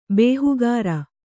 ♪ bēhugāra